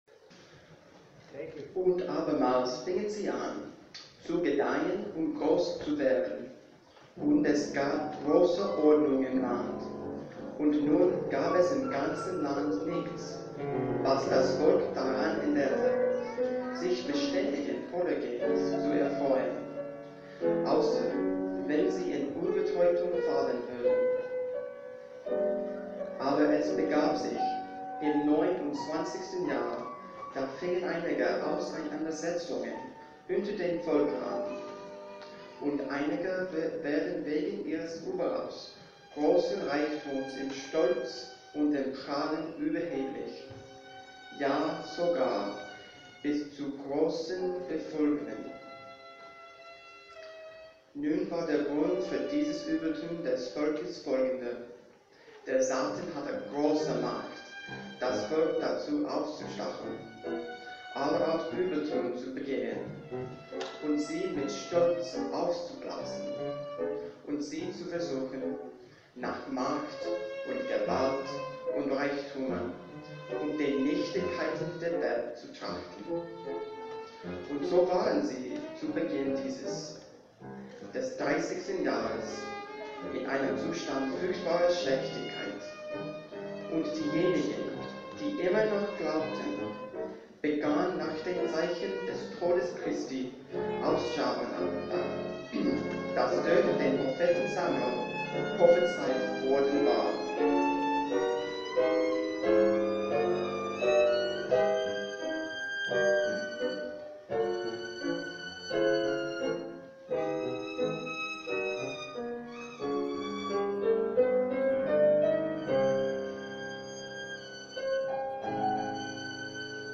Soprano, Narrator, SATB Chorus, Violin, and Piano